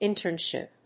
音節íntern・shìp 発音記号・読み方
/ˈɪntɝˌnʃɪp(米国英語), ˈɪntɜ:ˌnʃɪp(英国英語)/